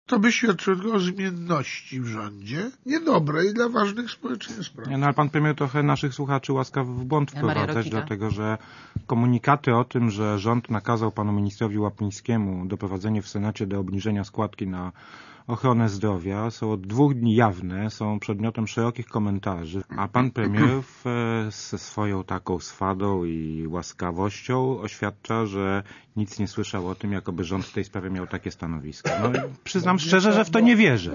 "Irytujący bałagan" - tymi słowami wszyscy goście programu Radia Zet -"Siódmy dzień Tygodnia" określają zamieszanie wokół tego, jaką skłądkę na zdrowie mamy płacić w tym roku.
O tym, że w tej sprawie panuje gigantyczne zamieszanie świadczą wypowiedzi Józefa Oleksego z SLD i Janusza Wojciechowskeigo z PSL-u. Obaj ci politycy nawet nie wiedzą, że rząd zmienił stanowisko w tej sprawie.